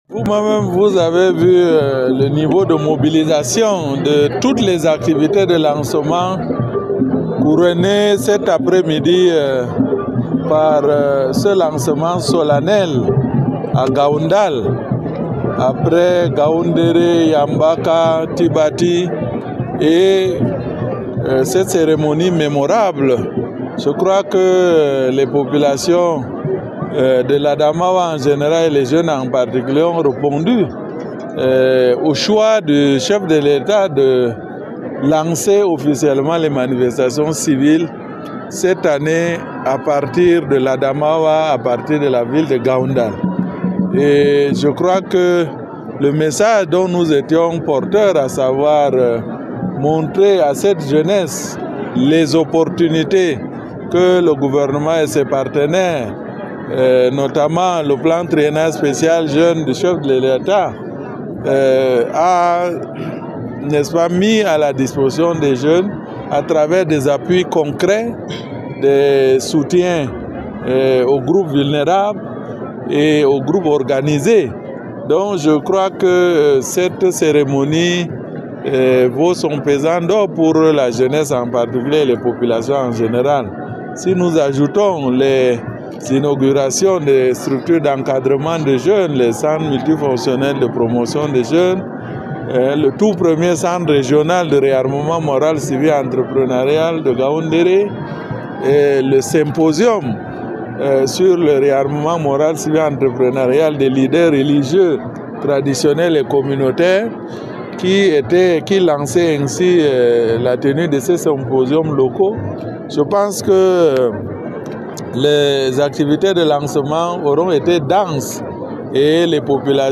Interview-lancement-Ngaoundal-FR.mp3